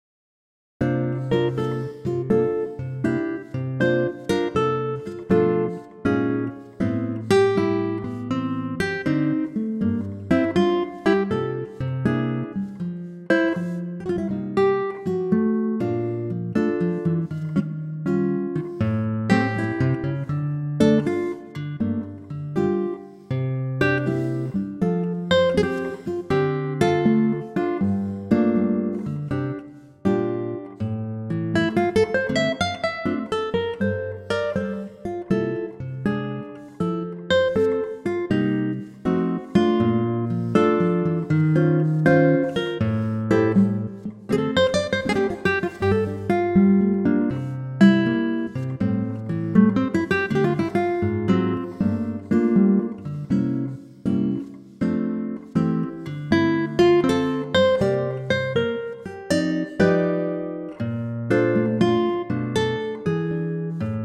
Unique Backing Tracks
key - F - vocal range - C to E
Gorgeous acoustic guitar arrangement